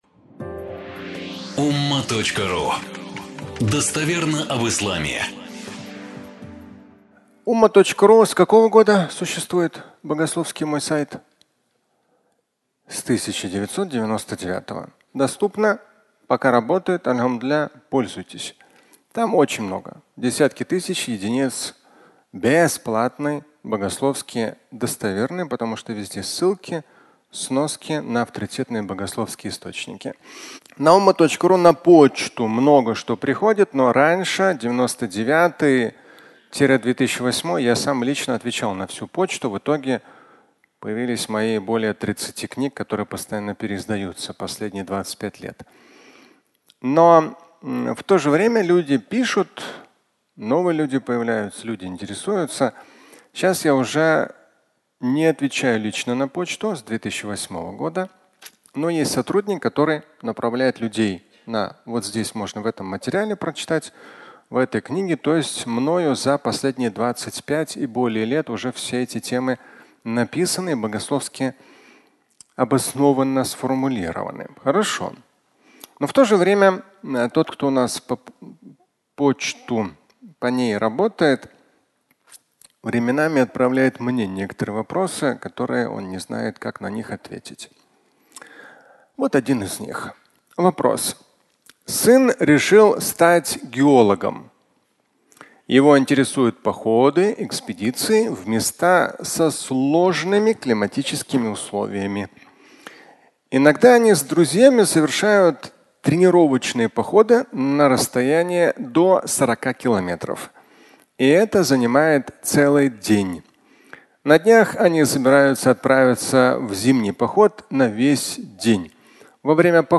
Намаз без омовения (аудиолекция)
Фрагмент пятничной лекции, в котором Шамиль Аляутдинов говорит о молитве без малого омовения и объясняет, как совершать намаз в затруднительных ситуациях.